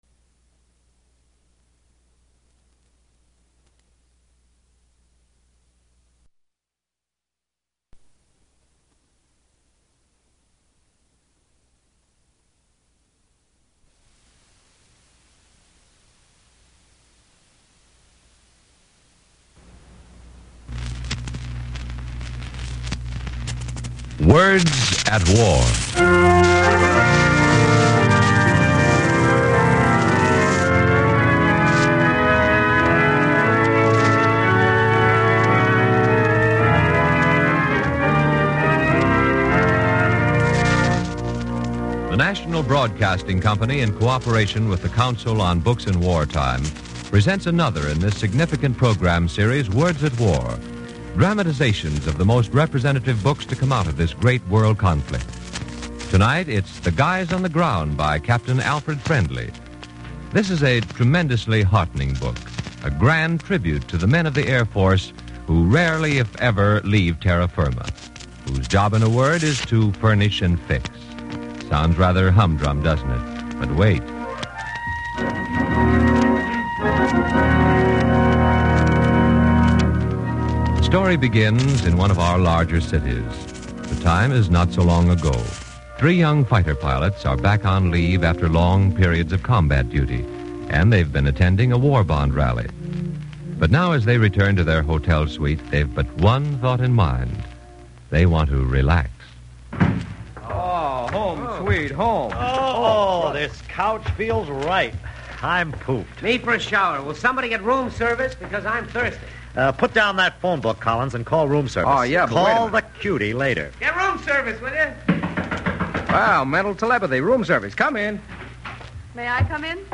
Words At War, the series that brings you radio versions of the leading war book another adaptation of an important war book, “The Guys On The Ground”.